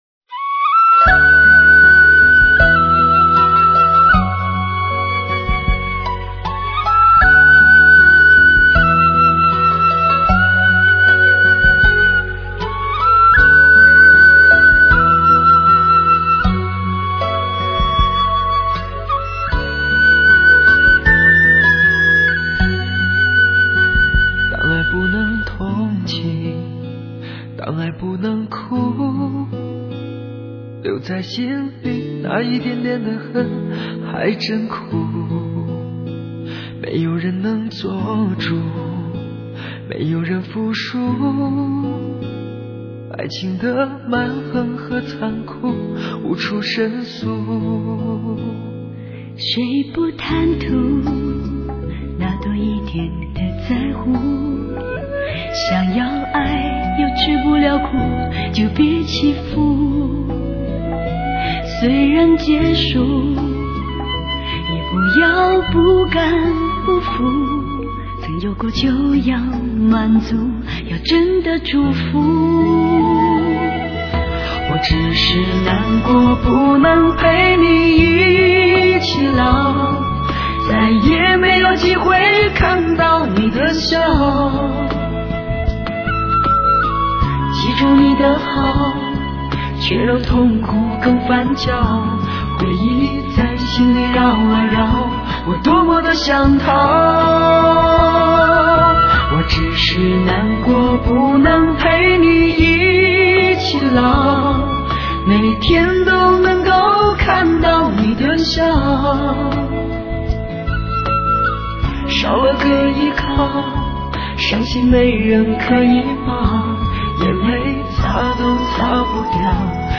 笛子/竹埙/排箫
二胡
古筝
琵琶/柳琴
弹拨尔/萨塔尔
吉他
发烧界最佳女声 公认绝配好声音